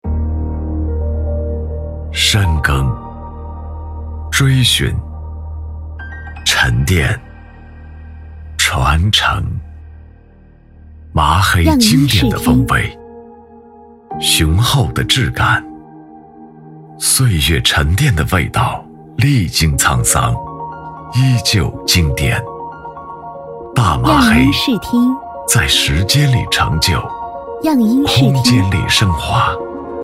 国语配音
男65-广告-精制大麻黑.mp3